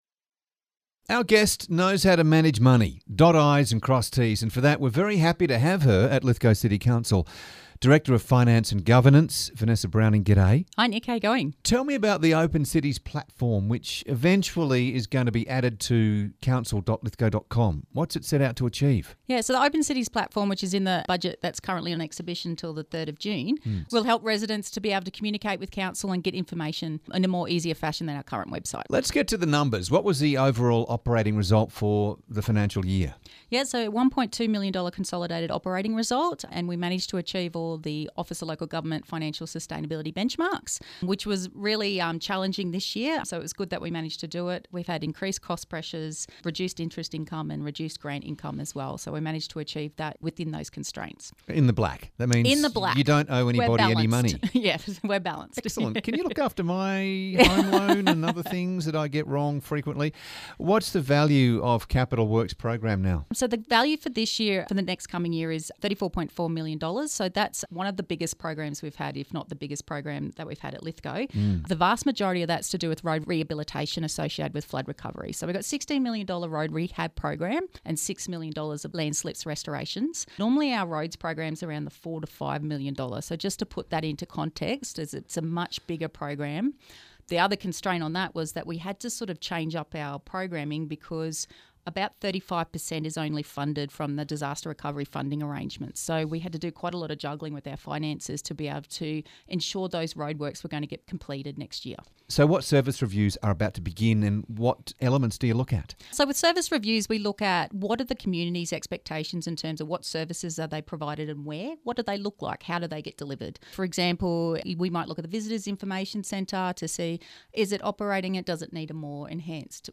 interviews
Audio courtesy of 2LT and Move FM